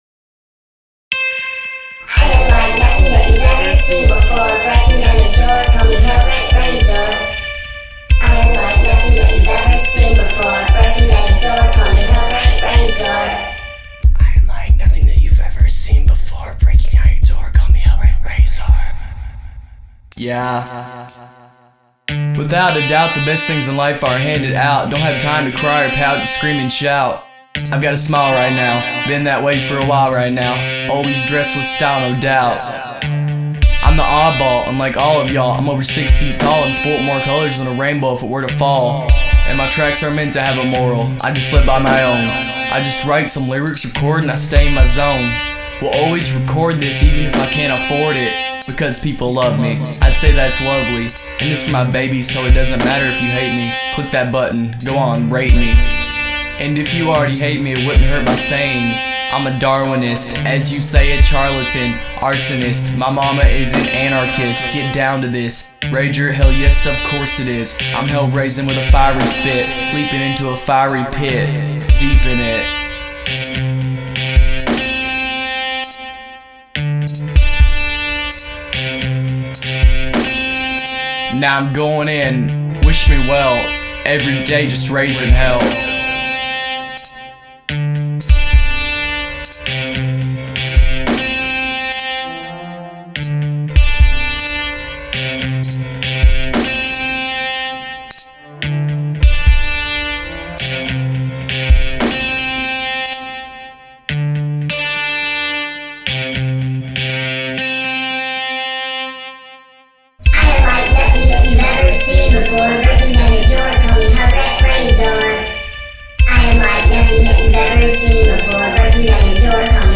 R&B/ Hip-Hop